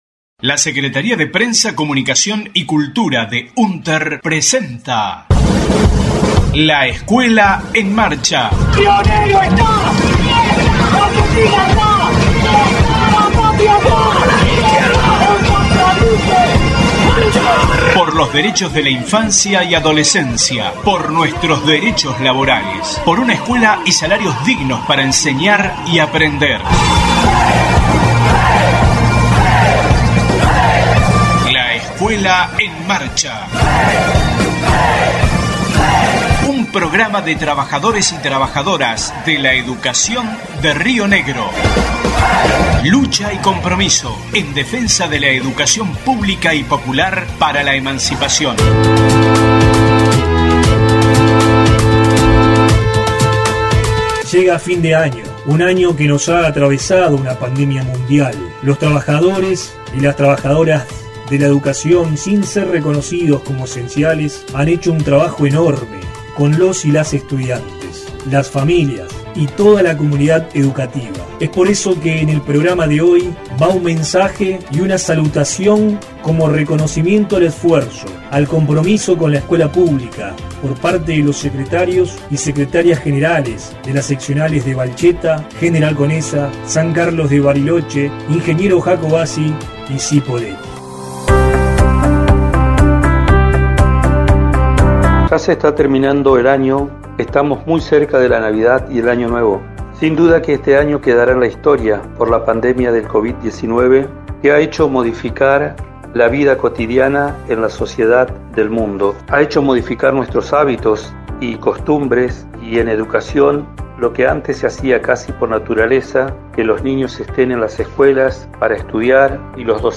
Voces de Secretarixs Generales